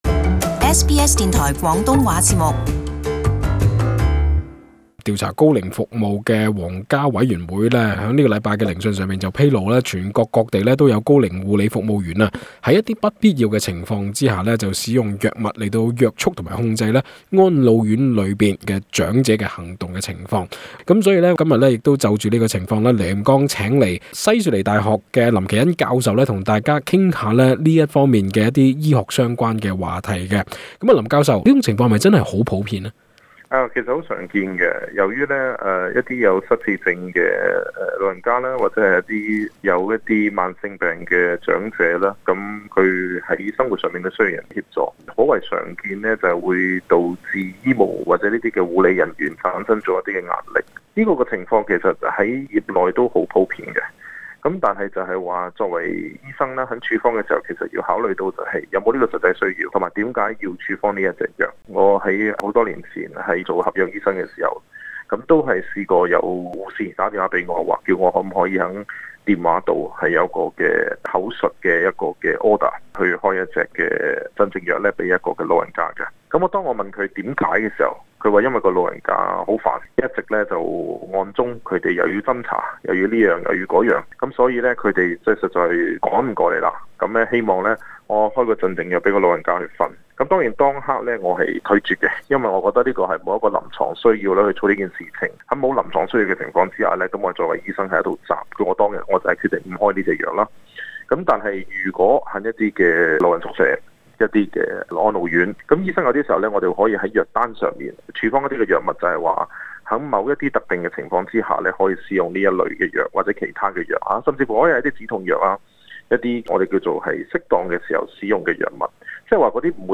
【社區專訪】如何得知住院長者是否被無理用藥？